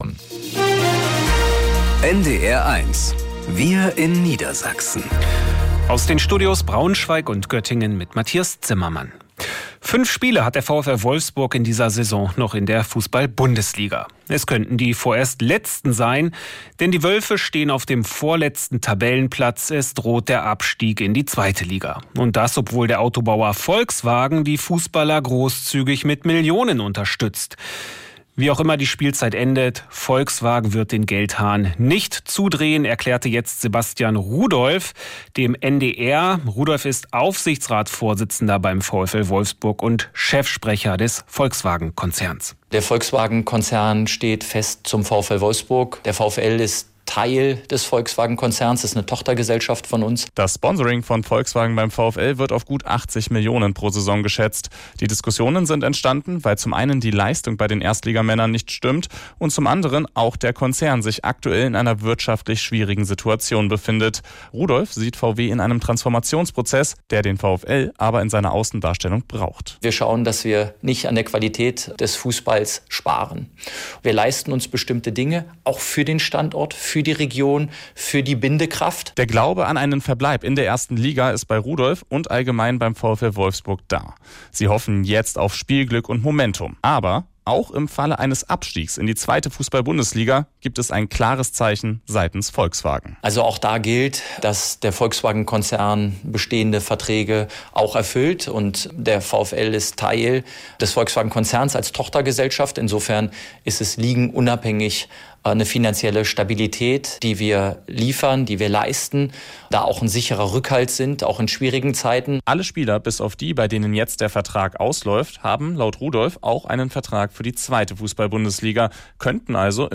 VW steht auch bei möglichem Abstieg zu Unternehmenstochter VfL-Fußball-GmbH ~ Wir in Niedersachsen - aus dem Studio Braunschweig | Nachrichten Podcast